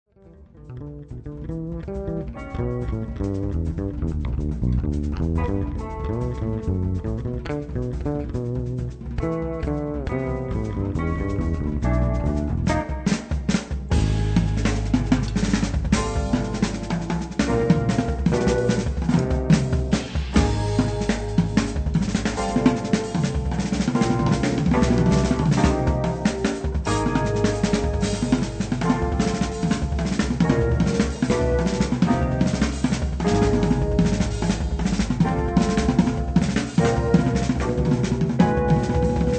in eight funk and latin originals.
Guitar
Fretless Electric Bass
Drums